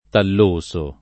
vai all'elenco alfabetico delle voci ingrandisci il carattere 100% rimpicciolisci il carattere stampa invia tramite posta elettronica codividi su Facebook talloso [ tall 1S o ] agg. (bot. «di tallo»; chim. «di tallio»)